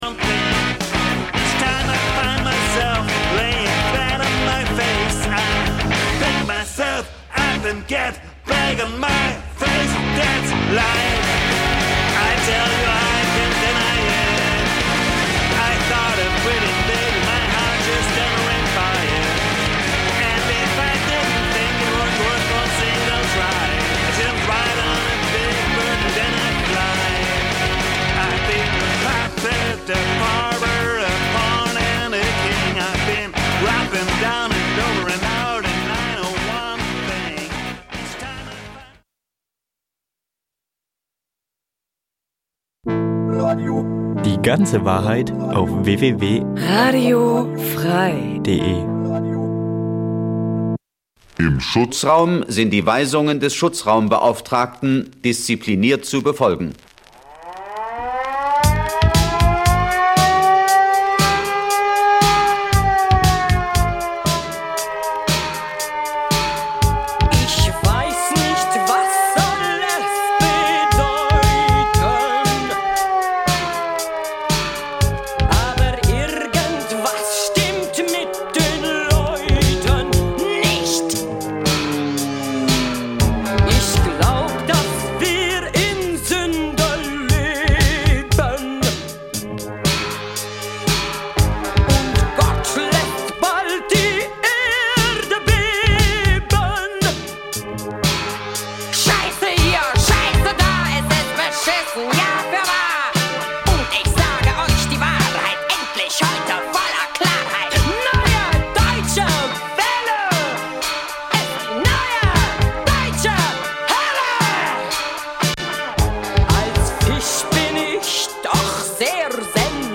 Punk '77 - '79 Dein Browser kann kein HTML5-Audio.